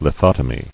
[li·thot·o·my]